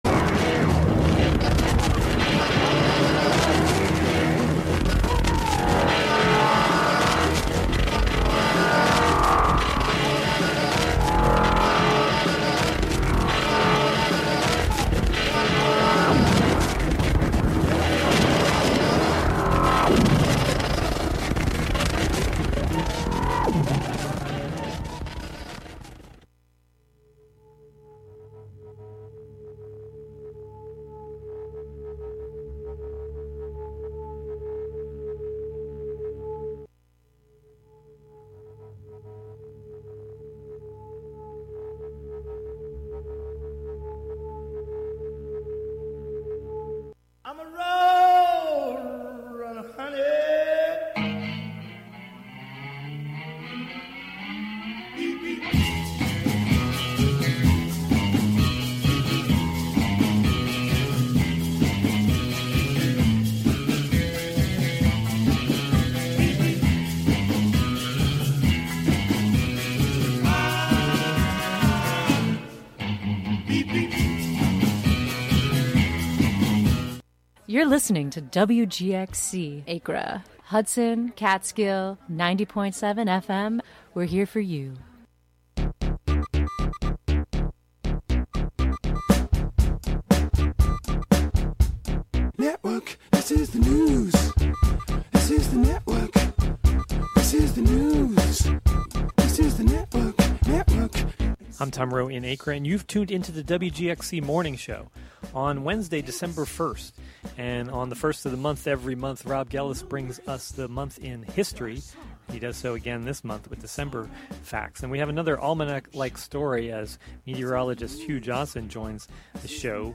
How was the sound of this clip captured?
Sometimes national, state, or local press conferences, meetings, or events are also broadcast live here.